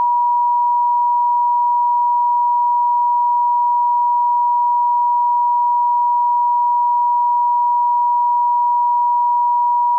963 Hz.wav